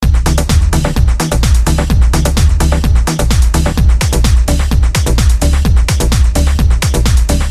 描述：朗朗上口的深宅循环集......最小的感觉，有3种变化可以玩。
标签： 128 bpm Deep House Loops Groove Loops 1.26 MB wav Key : Unknown
声道立体声